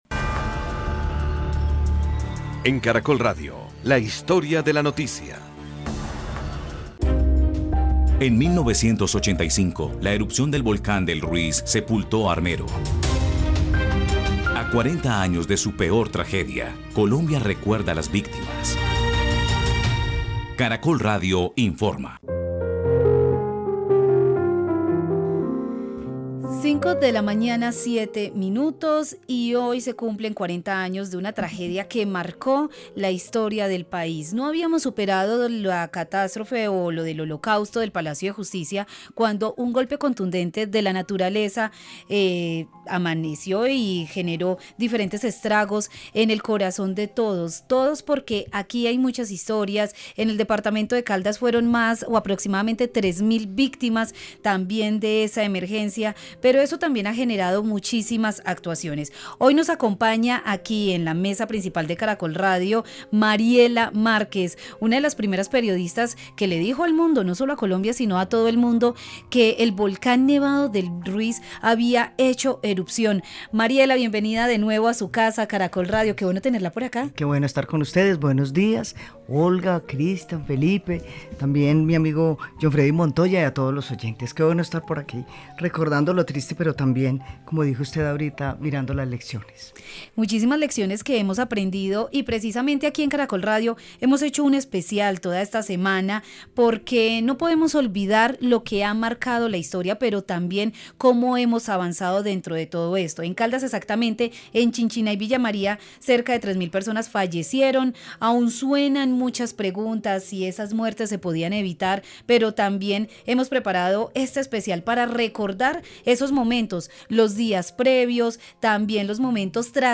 Especial realizado desde Manizales sobre la tragedia que dejó a más de 3 mil muertos en Villamaría y Chinchiná.